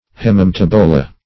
Hemimetabola \Hem`i*me*tab"o*la\, n. pl. [NL. See Hemi-, and